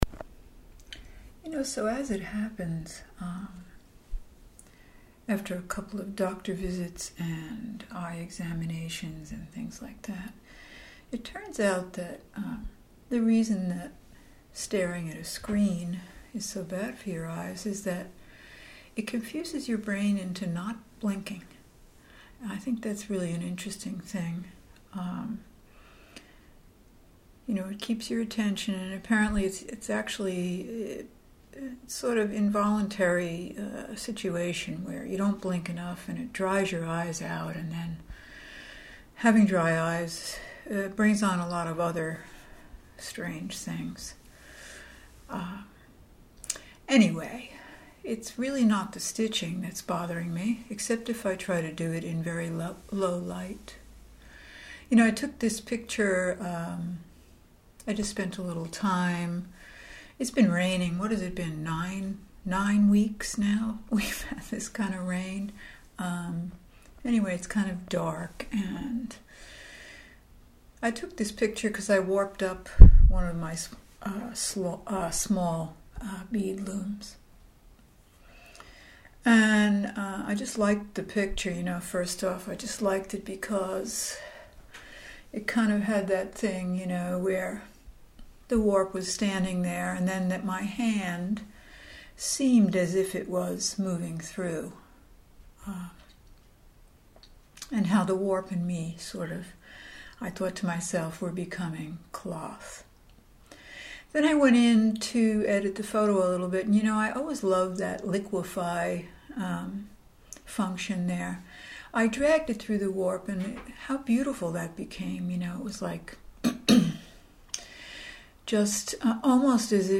So it seems talking is my best way forward. No editing, less screen time.